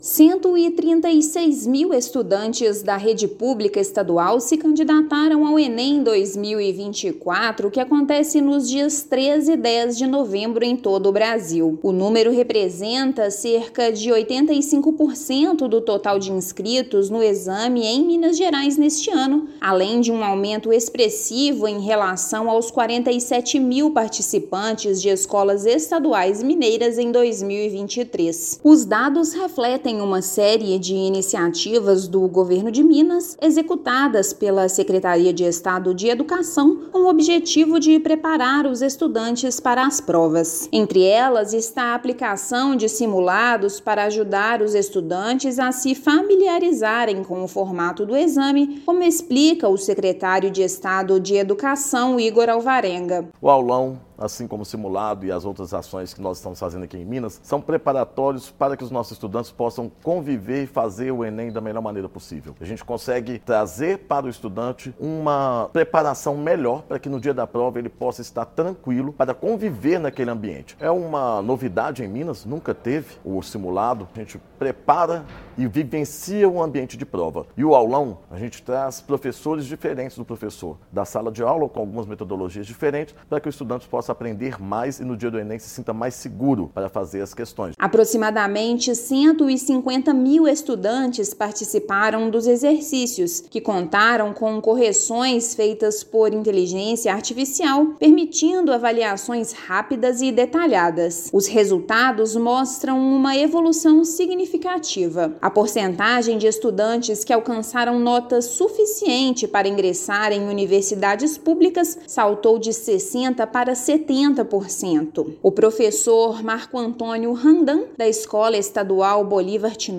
[RÁDIO] Rede estadual de ensino registra recorde de inscritos no Enem 2024
Com 136 mil inscritos, Governo de Minas promove iniciativas inovadoras e eficazes para garantir o sucesso dos estudantes da rede pública no exame; plataforma Enem MG já registrou 1 milhão de acessos. Ouça matéria de rádio.